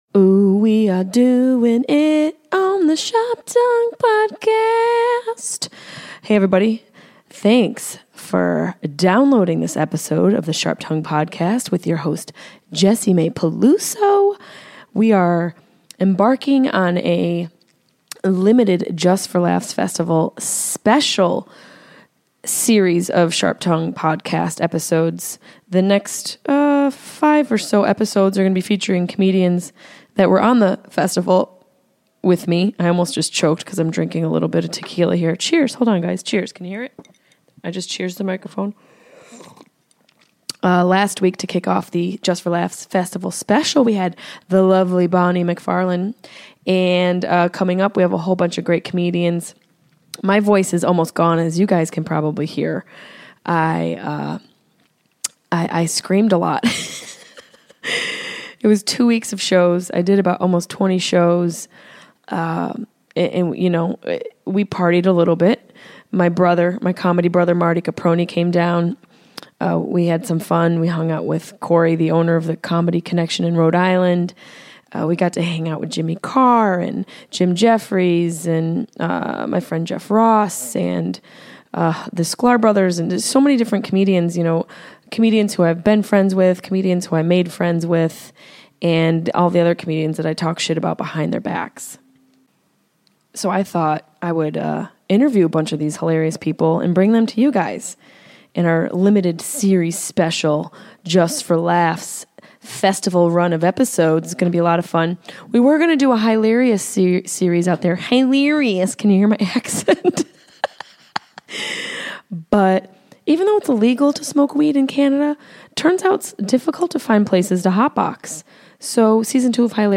Just For Laughs edition: Alonzo Bodden Live from Jessimae's hotel room at JFL in Montreal we bring you comedy legend, Alonzo Bodden.